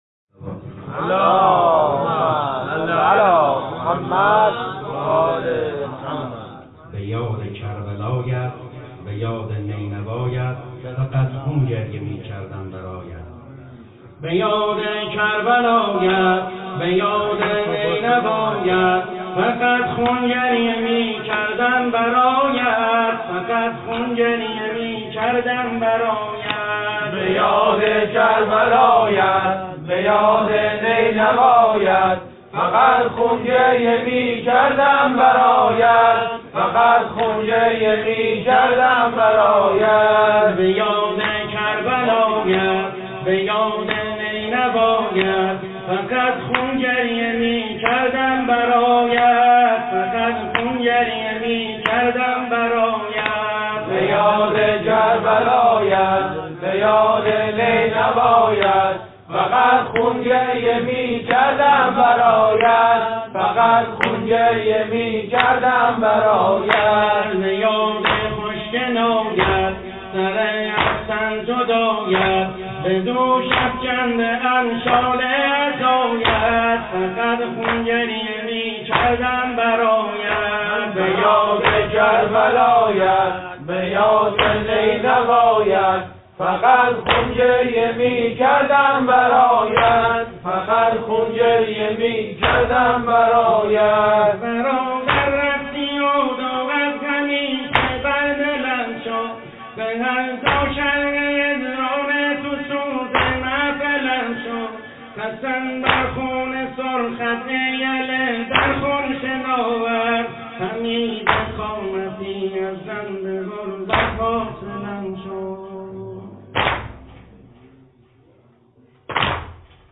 متن و سبک نوحه اربعین -( به یاد کربلایت به یاد نینوایت )